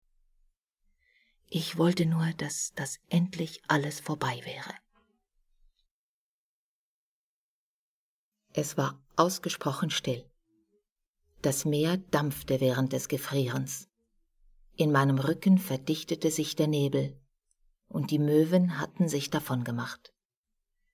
deutschsprachige Sprecherin (Schweiz) Verzauberung: großer Stimmumfang, schöne Modulation!
Sprechprobe: eLearning (Muttersprache):
german female voice over artist (switzerland)